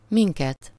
Ääntäminen
Synonyymit bennünket Ääntäminen Tuntematon aksentti: IPA: /ˈmiŋkɛt/ Haettu sana löytyi näillä lähdekielillä: unkari Käännös Ääninäyte Pronominit 1. us US Esimerkit Meghívtak minket .